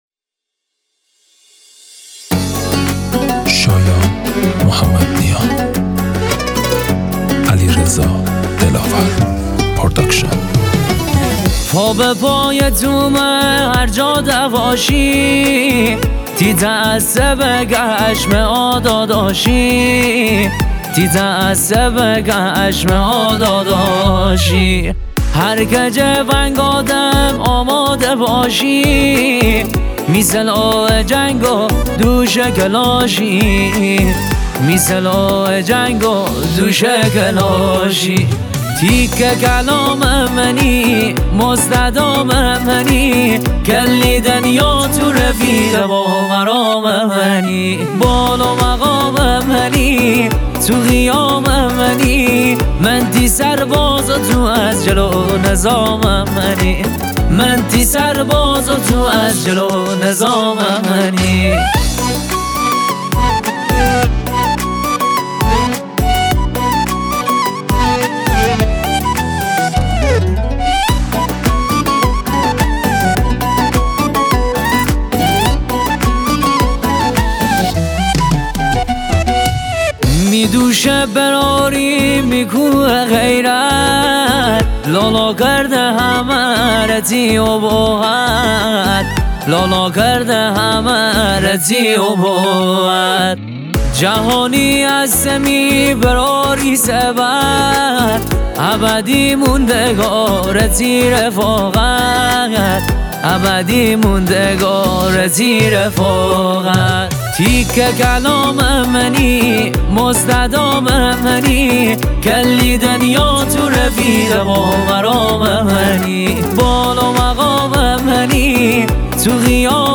شاد
آهنگ شاد مازندرانی